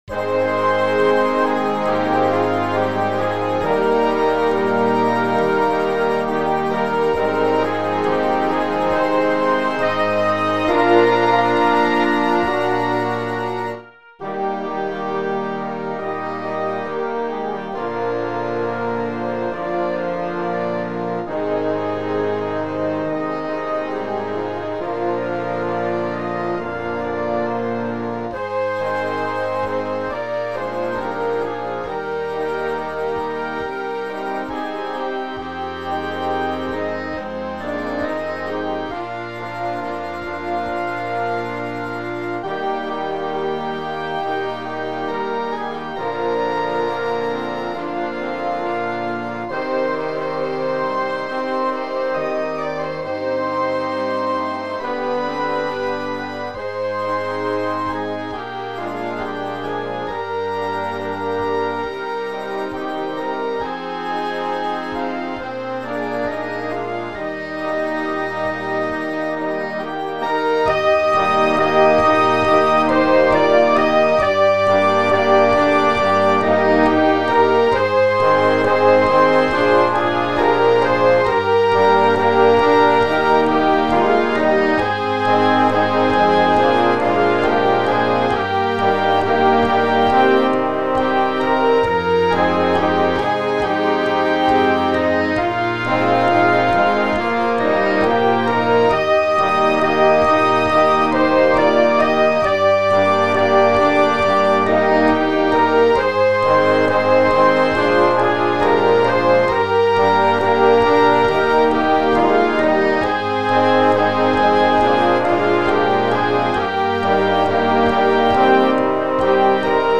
Disponibile in due versioni: per Banda e per Coro.